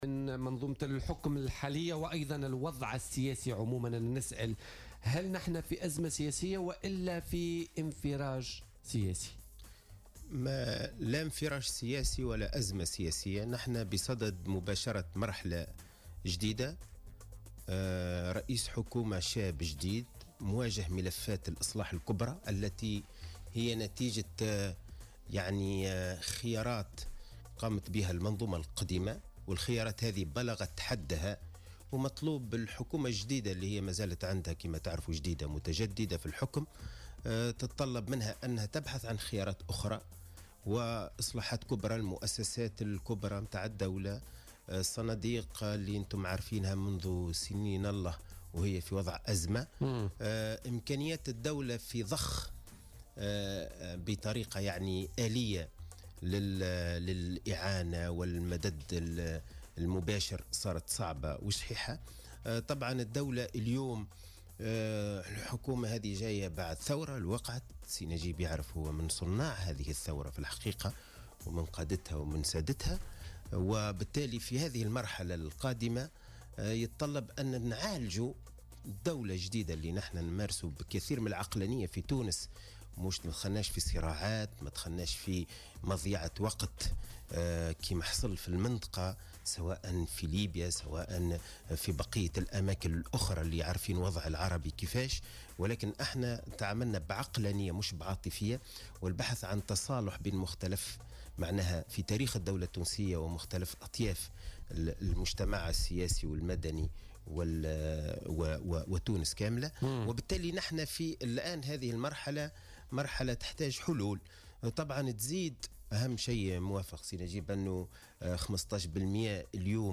أكد القيادي عن حركة النهضة حسين الجزيري ضيف بولتيكا اليوم الأربعاء 4 أكتوبر 2017 أن تونس بصدد مباشرة مرحلة جديدة في ظل وجود رئيس حكومة شاب يواجه ملفات الاصلاح الكبرى التي جاءت نتيجة خيارات قامت بها المنظومة القديمة.